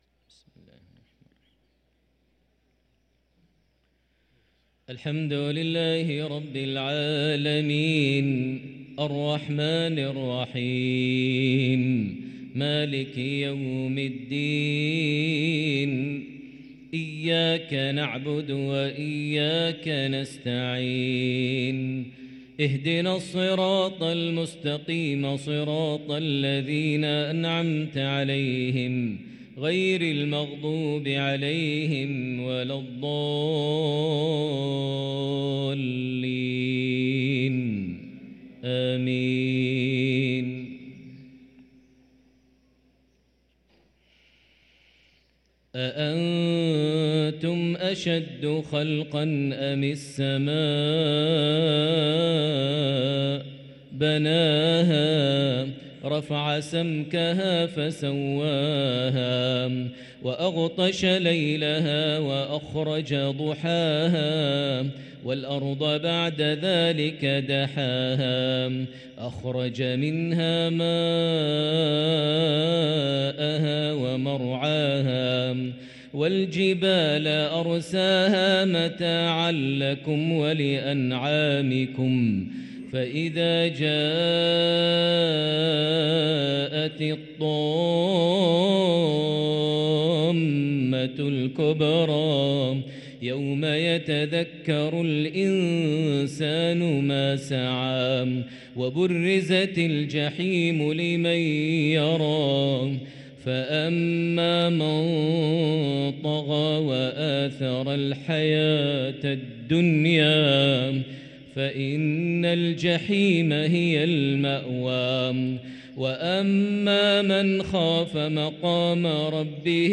صلاة المغرب للقارئ ماهر المعيقلي 3 شعبان 1444 هـ